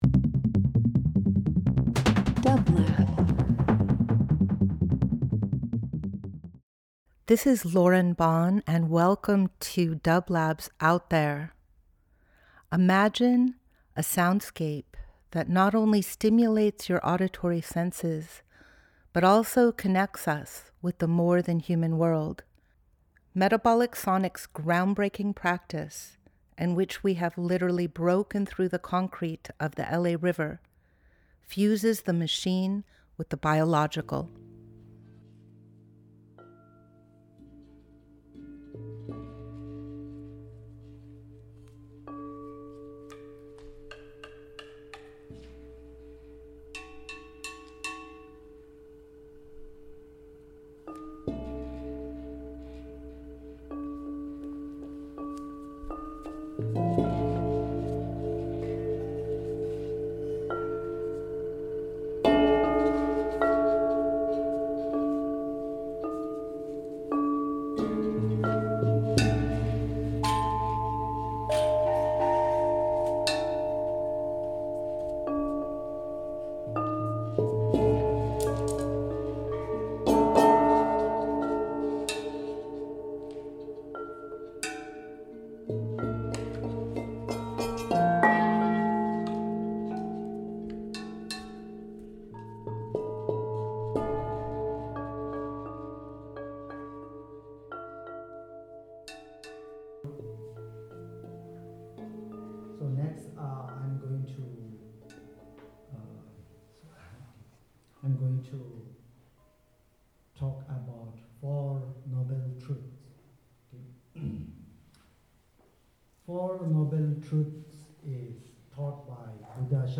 Each week we present field recordings that will transport you through the power of sound.
Metabolic Sonics Metabolic Studio Out There ~ a field recording program 01.22.26 Ambient Field Recording Spiritual Voyage with dublab into new worlds.